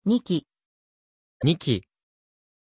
Aussprache von Nici
pronunciation-nici-ja.mp3